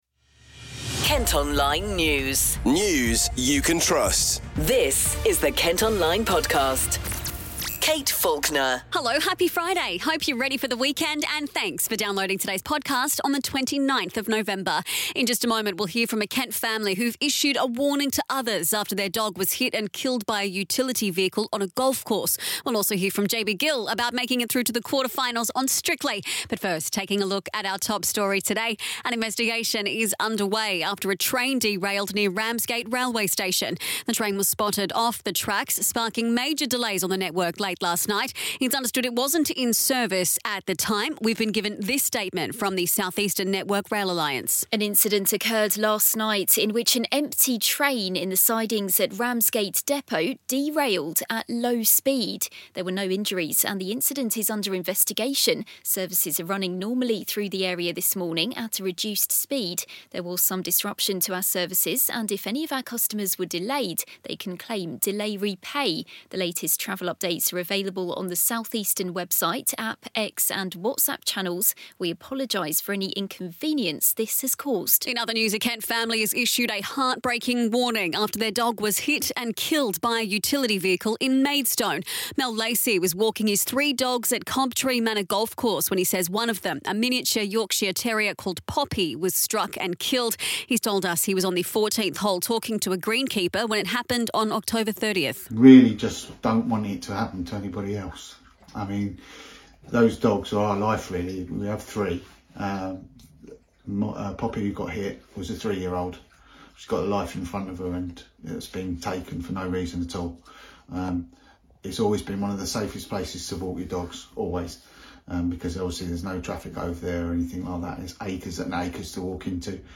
And you can hear from JLS star JB Gill on how he’s feeling now he’s made it through to the semi-finals on Strictly.